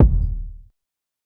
MANNIE_FRESH_kick_one_shot_noise.wav